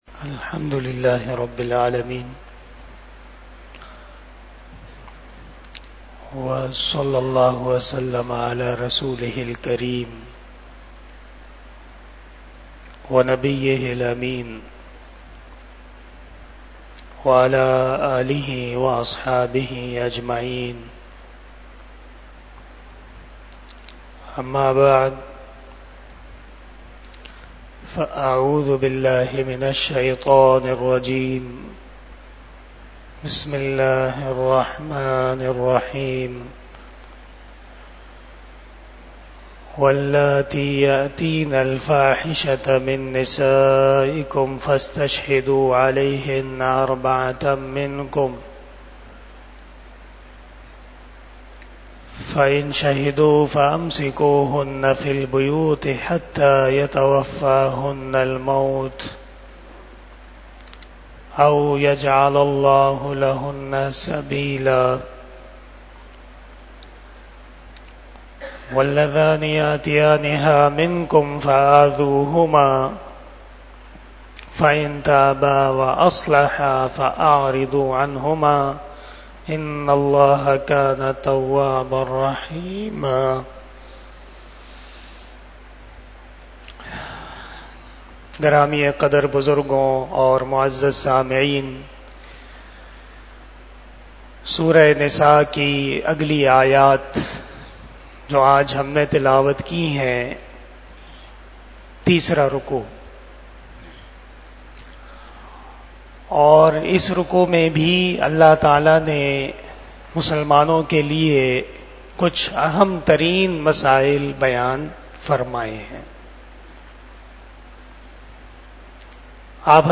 18 Shab E Jummah Bayan 06 June 2024 (29 Zil Qadah 1445 HJ)